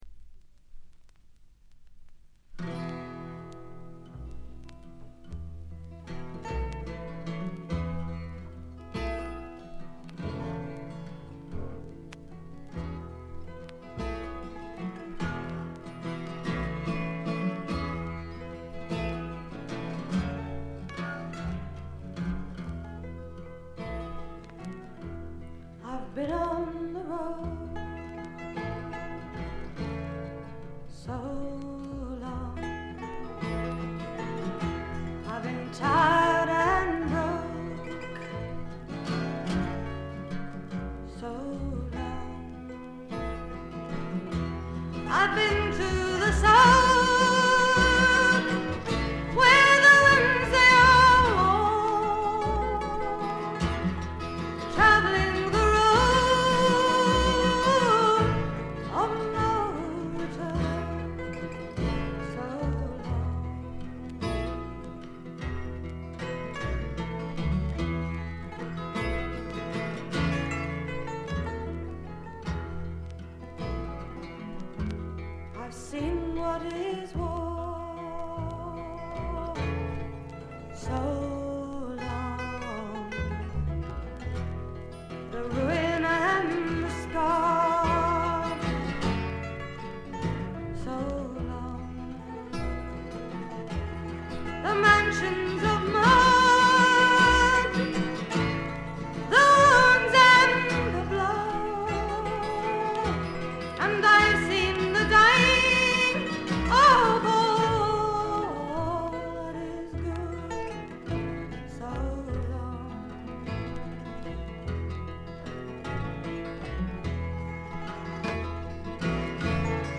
ホーム > レコード：英国 SSW / フォークロック
B5中盤7回ほど周回ノイズ。
試聴曲は現品からの取り込み音源です。